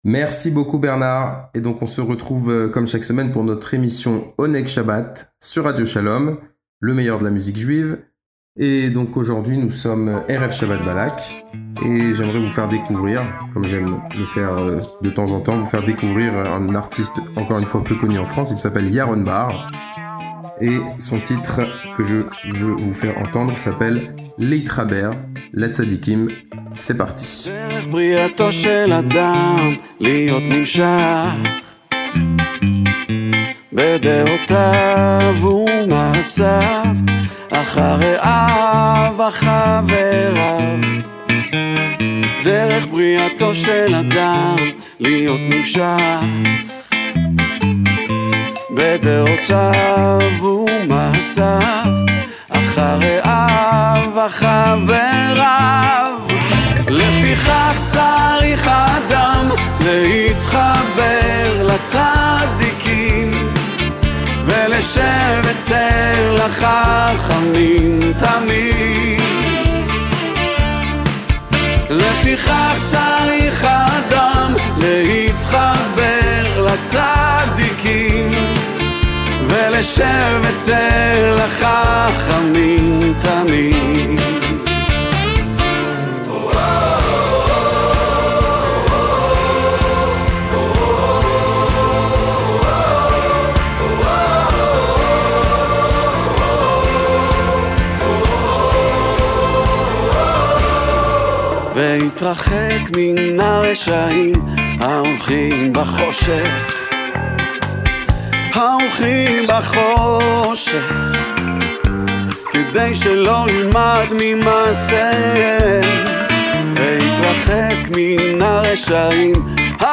le meilleur de la musique juive